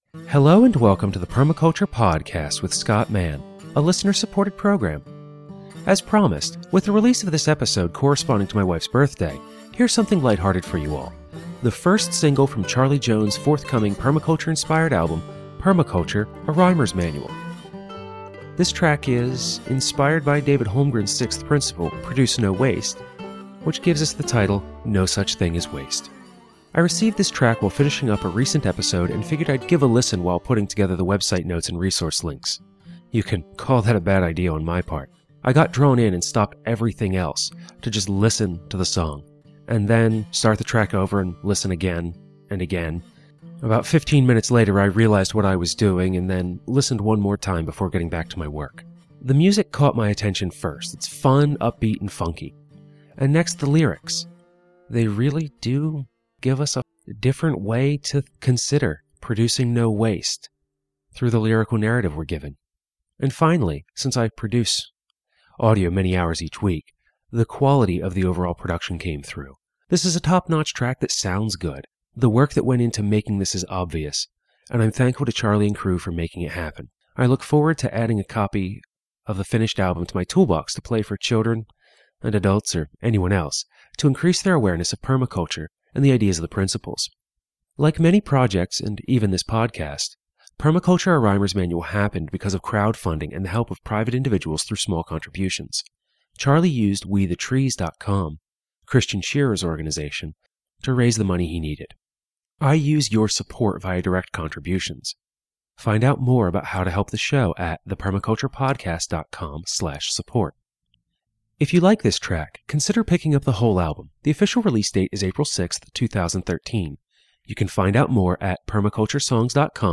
The music caught my attention first: fun, upbeat, and funky.
This is a top-notch track that sounds good.